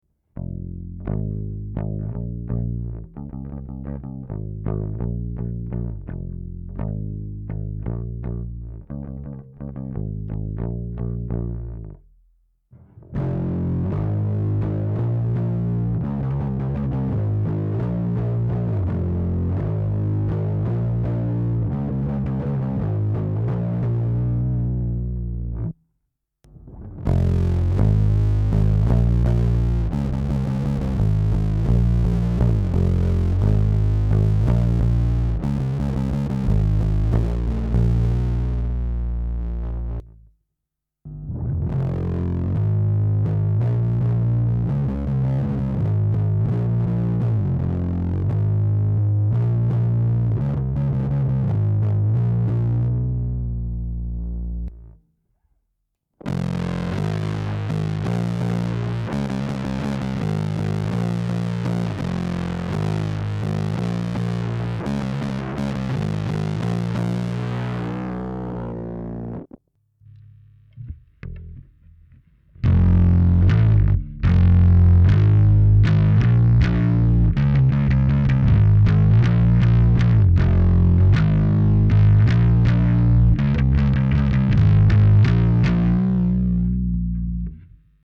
Basen är min Lakland skyline med darkstar-mick i P-position.
Bas->Effekter->FW-ljudkort.
Ordningen är: Rent ljud -> Digitech Bad Monkey -> Bass Big Muff (Dry-läge) -> El Grande Bass Fuzz -> Barker Assmaster -> Sansamp Bass Driver DI.
Ljudnivåerna är lite ojämna, särskilt sansampen eftersom jag normalt sett har lägre drive på och istället driver den till argfuzz med någon annan pedal.[/url]
Gain står runt kl 3.